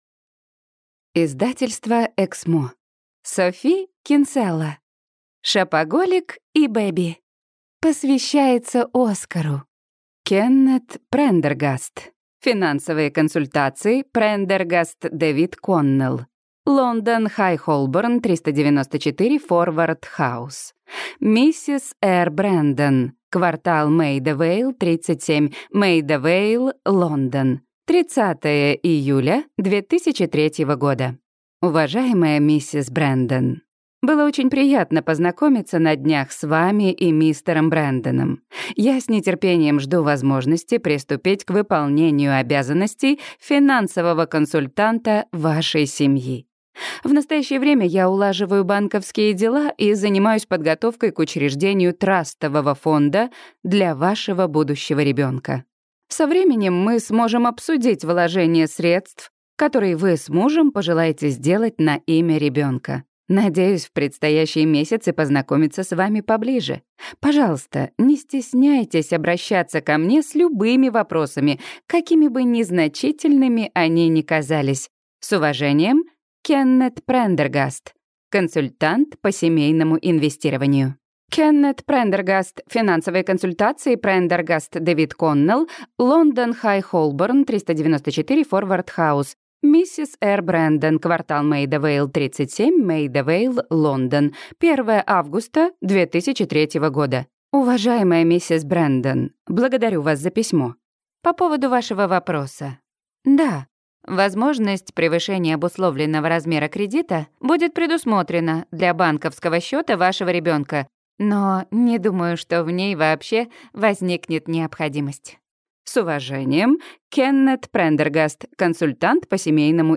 Аудиокнига Шопоголик и бэби | Библиотека аудиокниг